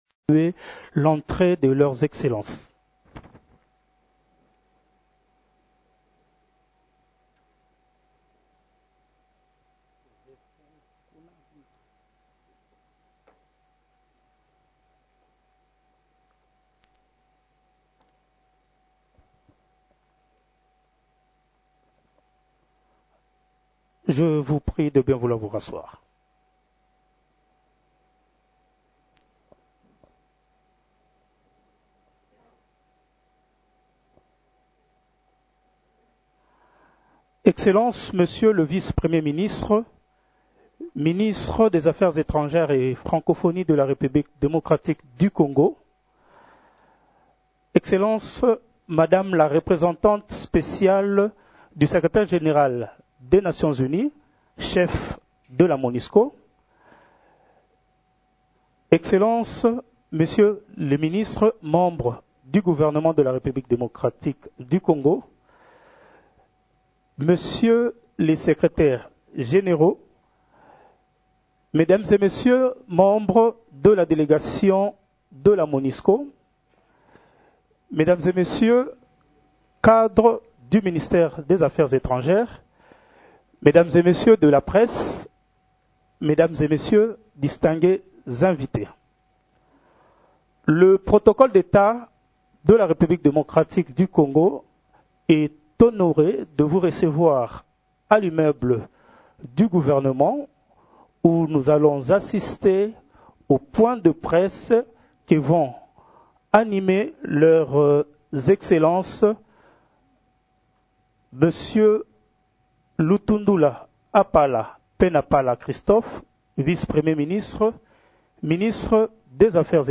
Conférence de presse conjointe MONUSCO-Gouvernement congolais
Ils l’ont dit lors d’une conférence de presse conjointe tenue ce samedi 13 janvier à Kinshasa.